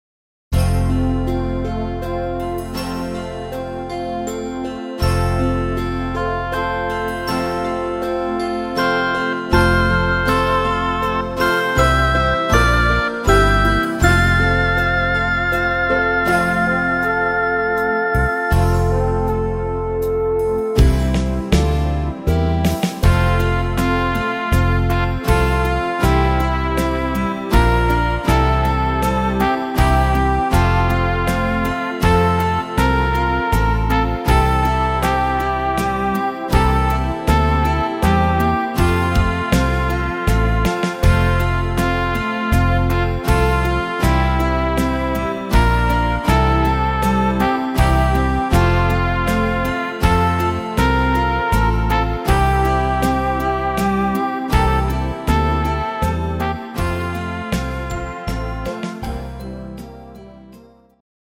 instr.Trompete